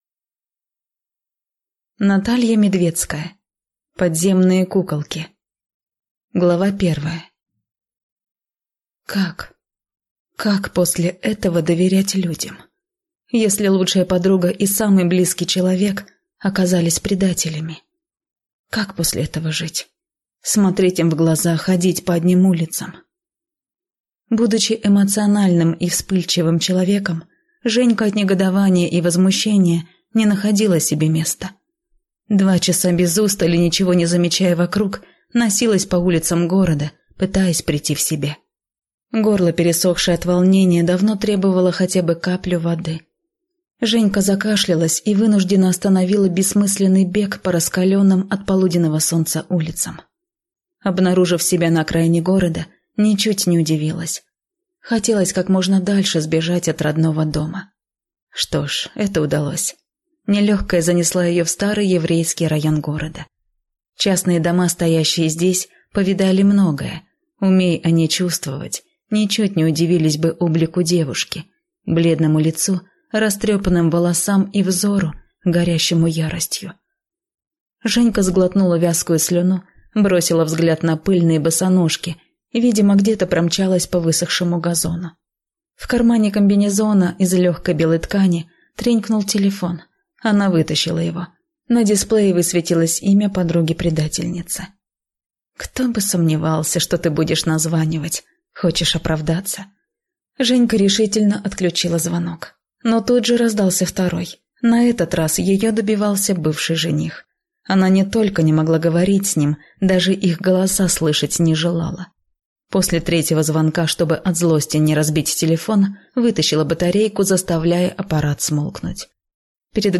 Аудиокнига Подземные куколки | Библиотека аудиокниг